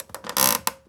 chair_frame_metal_creak_squeak_15.wav